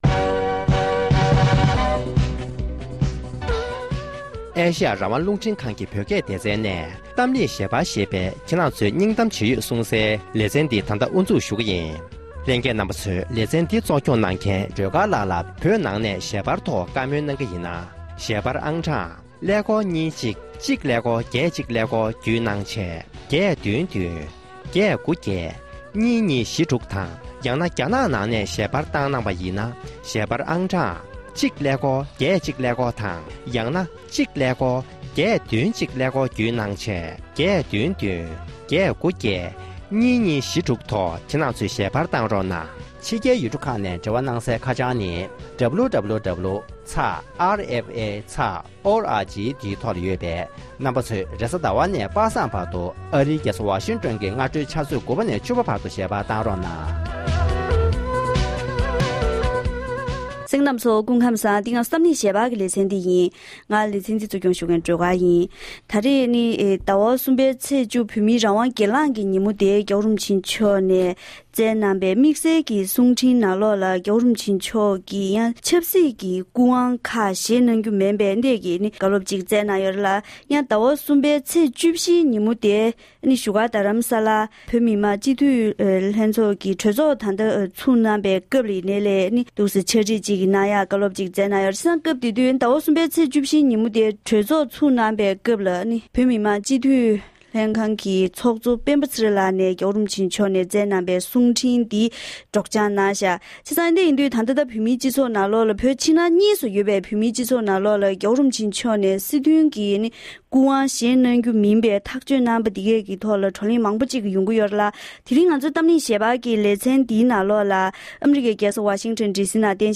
གཏམ་གླེང་ཞལ་པར་
འབྲེལ་ཡོད་མི་སྣར་གླེང་མོལ་ཞུས་པར་